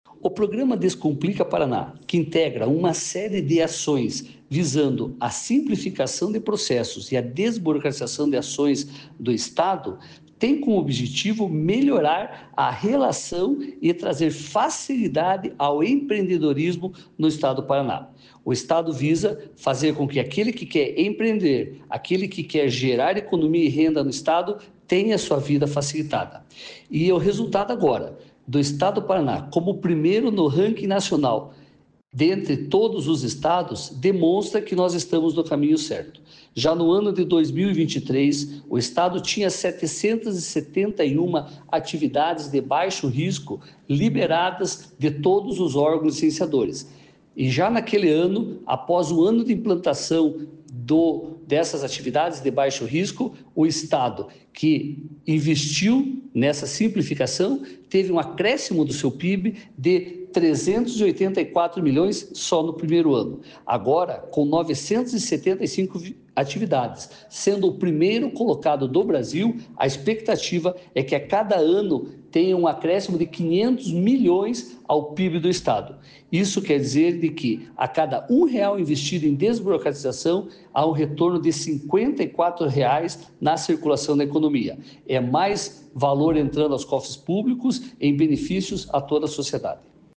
Sonora do presidente do Comitê Permanente de Desburocratização da Casa Civil, Jean Rafael Puchetti Ferreira, sobre 1º lugar no ranking nacional de dispensa de alvarás